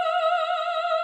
opera singer with heavy vibrato (grabbed from Youtube), and compare its spectrum with that of a 1-second clip of a
This is because the opera singer oscillates her pitch over quite a large range several times during the course of the sound clip.
opera.wav